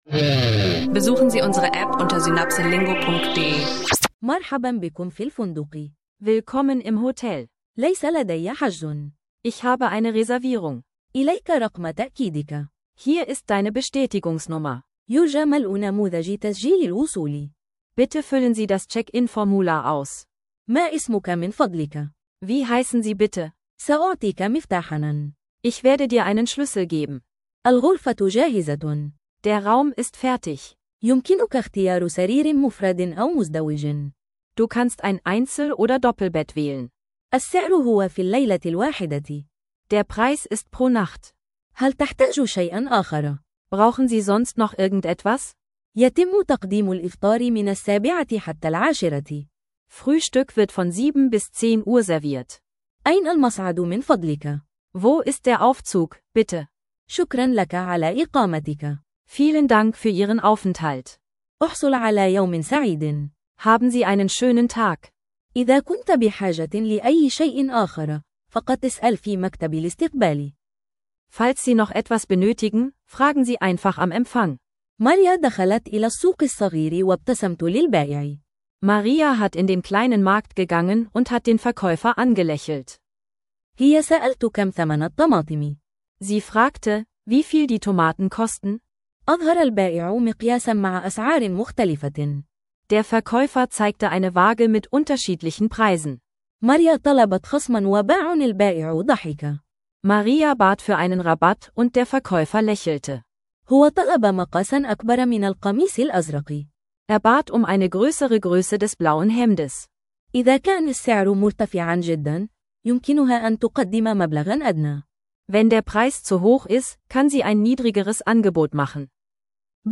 Verstehen Sie einfache Hotel- und Einkaufsdialoge auf Arabisch – praxisnaher Sprachkurs